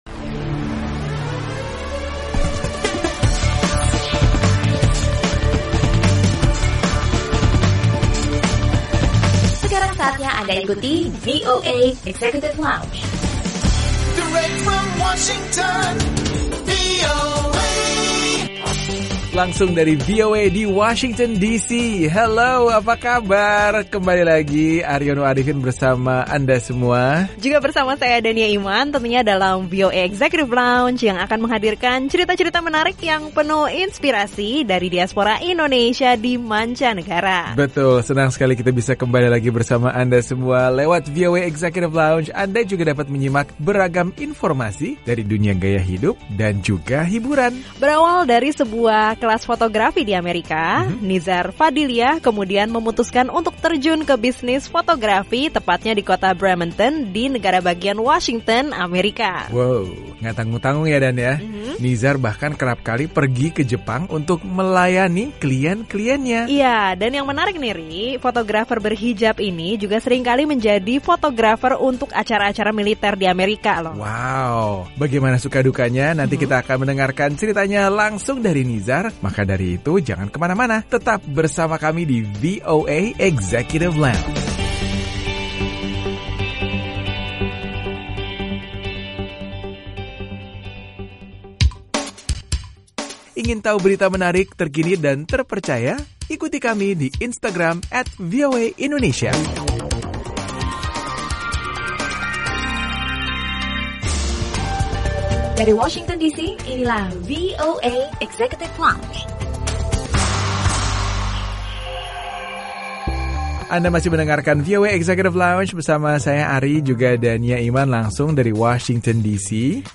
Obrolan bersama diaspora Indonesia di negara bagian Washington yang berbagi pengalamannya menjadi seorang portrait photographer professional di Amerika dan Jepang.